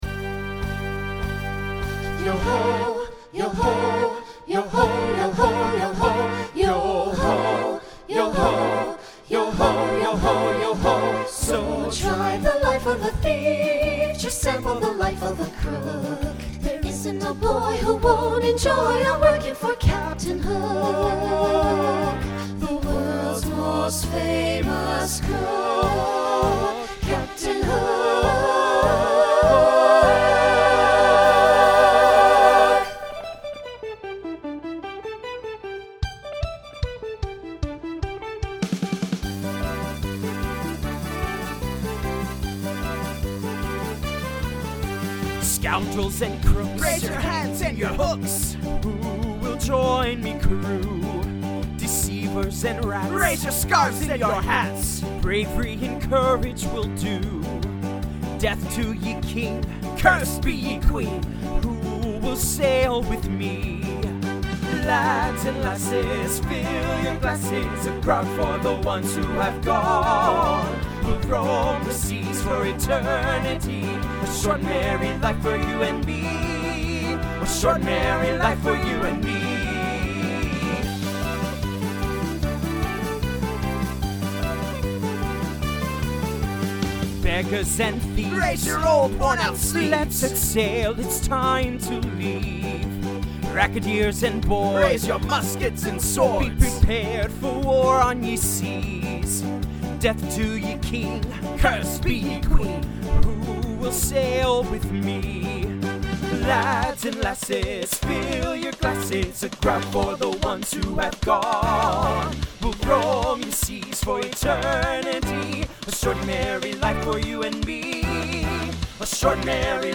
SATB
SSA with male solo
Genre Broadway/Film , Pop/Dance , Rock
Voicing Mixed